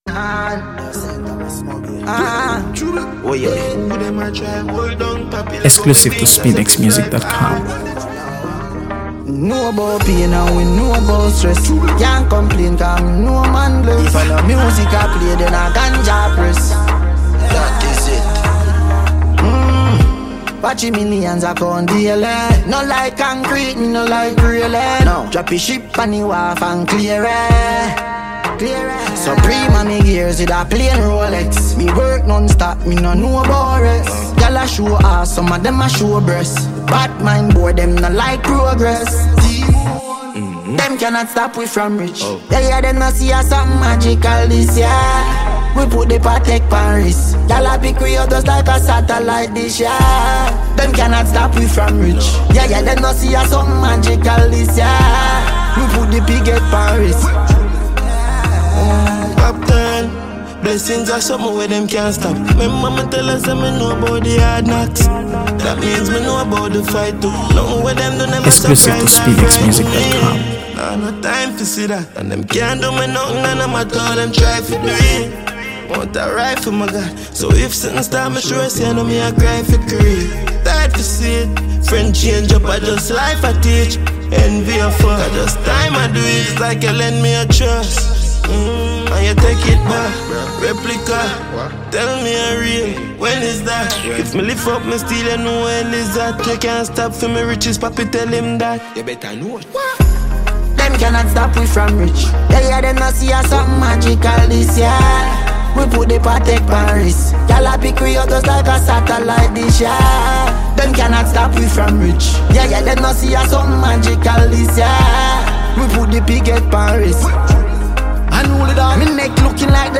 AfroBeats | AfroBeats songs
With its infectious rhythm and smooth flow